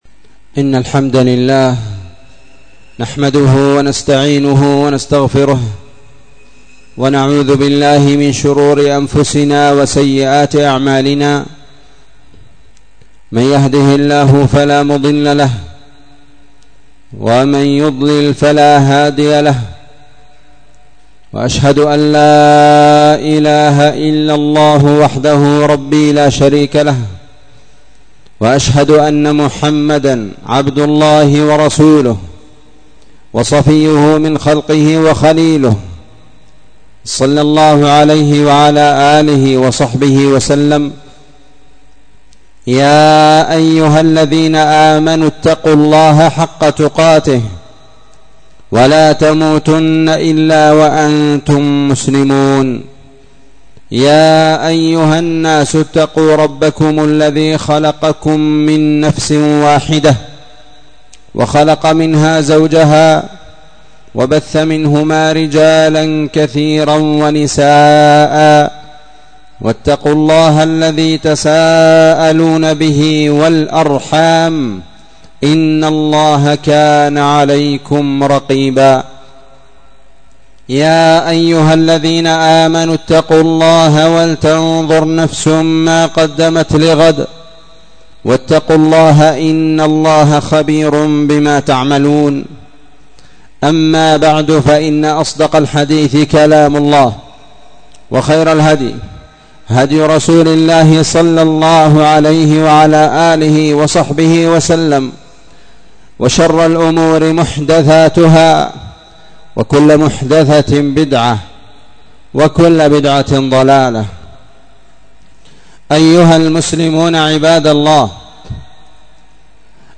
27:37 -مسجد المجاهد مسجد أهل السنة والجماعة تعز 25 محرم
خطبة الجمعة